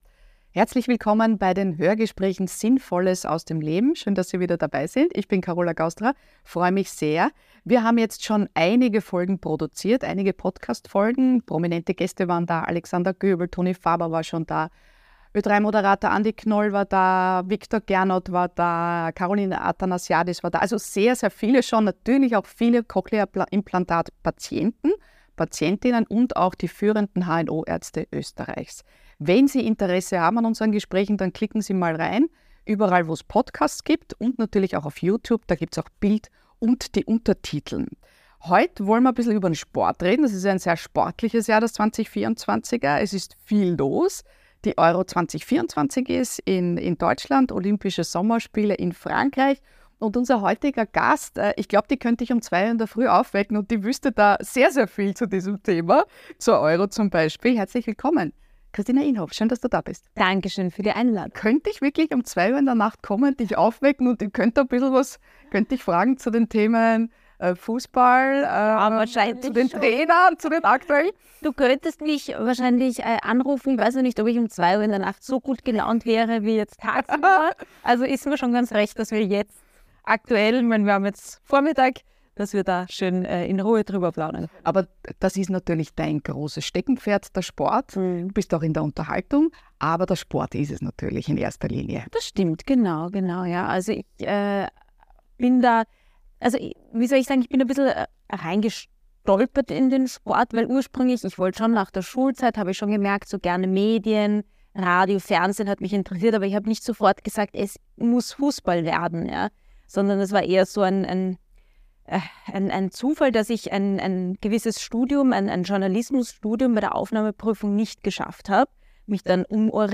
Und darüber kann sie viel erzählen: von emotionalen Momenten, den Arbeitsalltag mit Knopf im Ohr und wie man lernt, in ihrem Job wirklich zuzuhören. Dass Hören gerade beim Sport unverzichtbar ist, bestätigt Kristina Inhof im Interview, auch wenn sie im aufgeheizten Fußballstadion manchmal auf einiges, was es dort zu hören gibt, verzichten könnte.